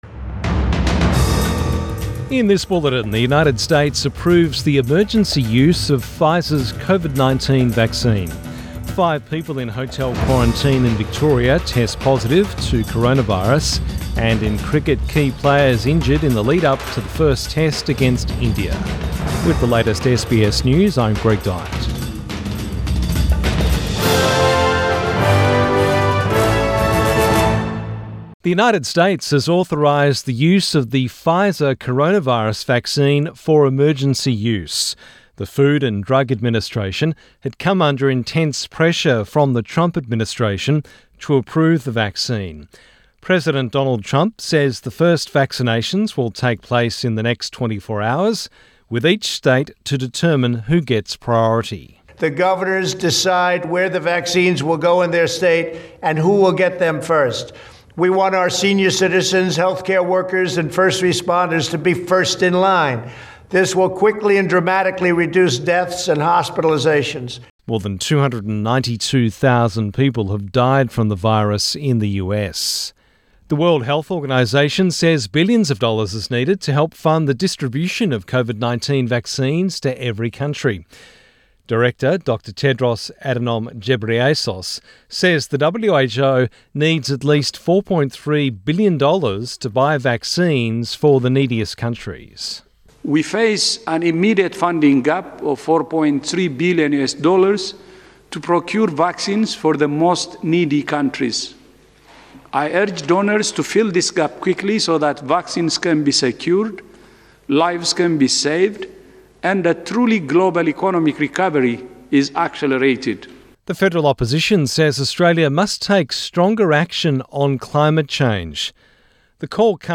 PM bulletin 12 December 2020